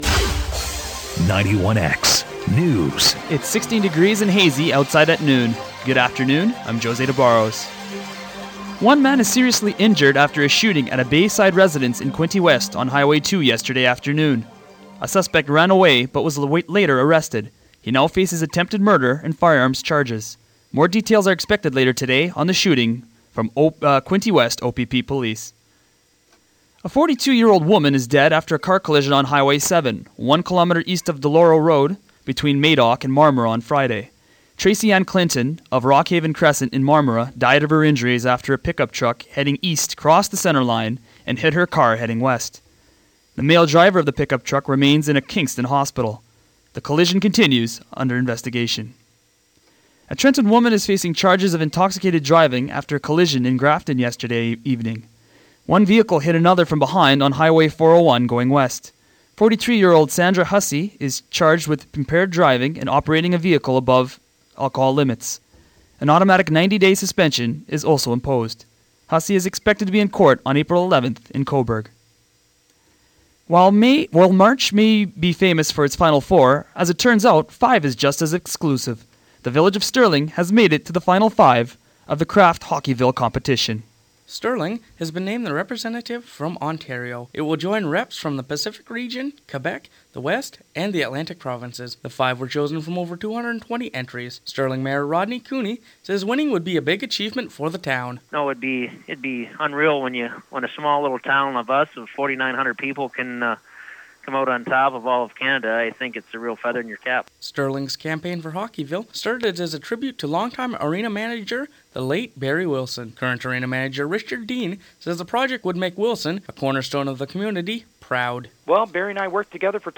Quinte's Most Comprehensive News Source